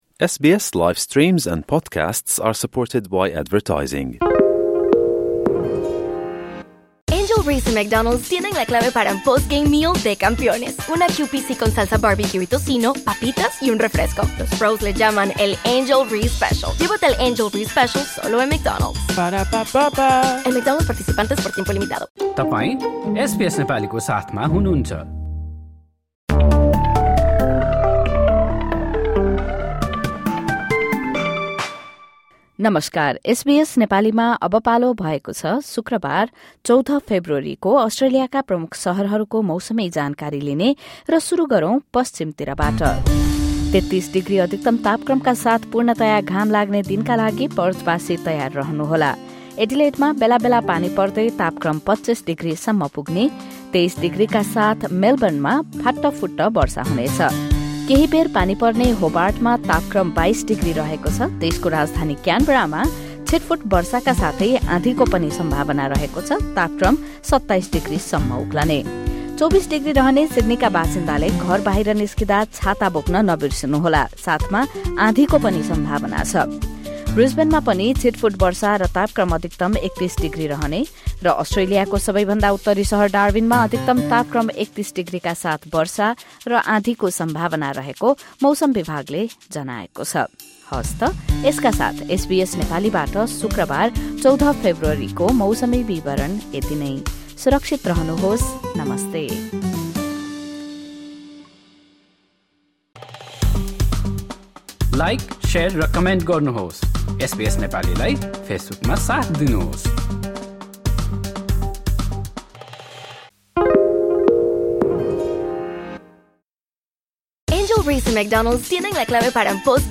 एसबीएस नेपाली अस्ट्रेलियन मौसम अपडेट: शुक्रवार, १४ फेब्रुअरी २०२५